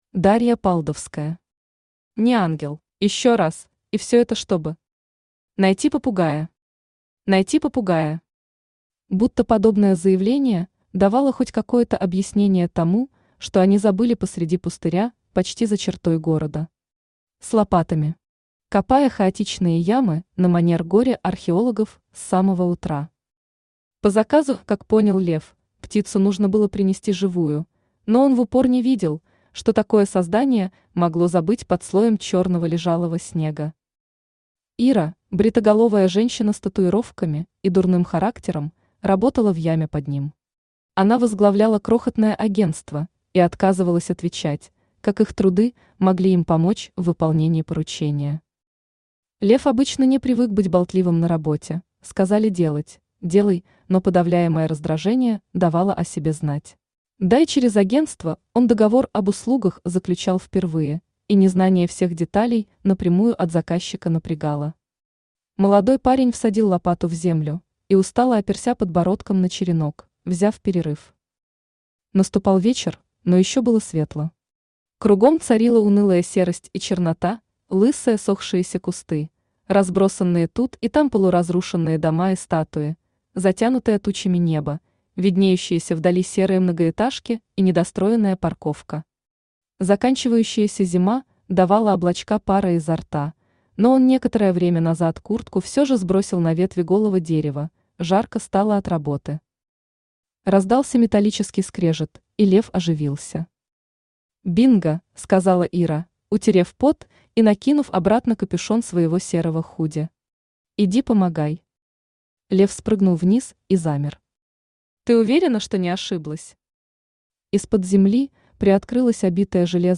Aудиокнига Не Ангел Автор Дарья Палдовская Читает аудиокнигу Авточтец ЛитРес. Прослушать и бесплатно скачать фрагмент аудиокниги